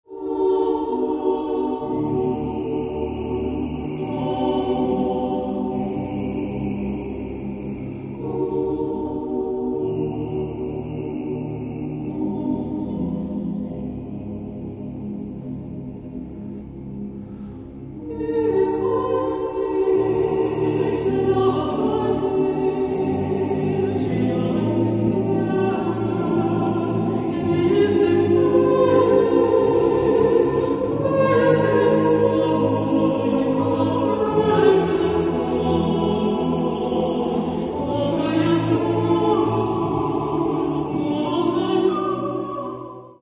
Catalogue -> Classical -> Choral Art